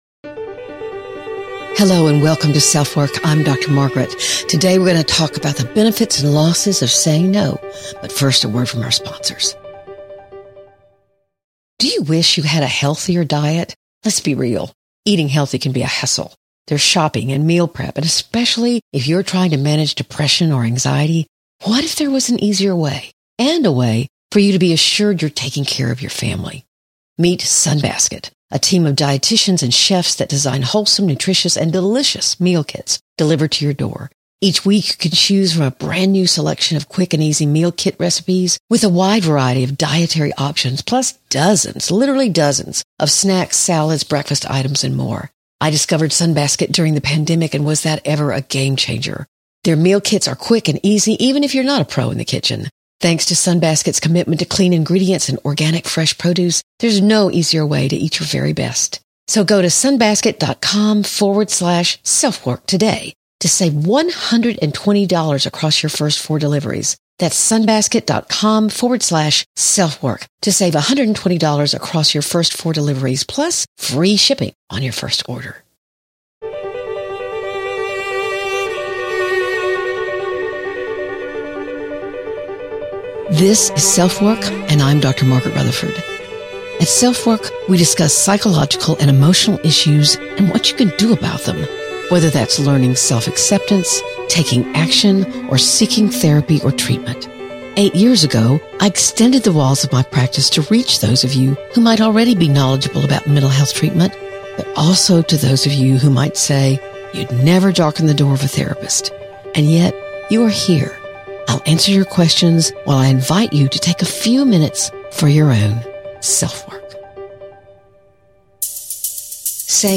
We’ll focus on how much of your decision-making is affected by depression or anxiety, or even personality disorders that have a huge bearing on how you decide to say no. Our voicemail today is from a young woman whose mother is highly volatile at home – but who can be quite loving and wonderful at other times.